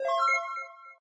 powerup4.ogg